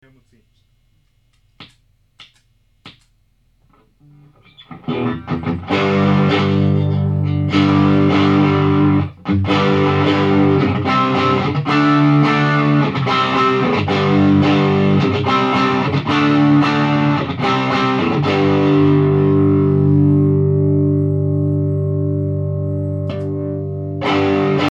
手持ちのやつらを動員しただばよぅ、2時間で実験したのは歪み系だば。
録音したMDから雑音のひどいものを取り除き、アップだば。
思うだば。マクソンがさすがにチューブ・サウンド、SD-2のリードモードは太めに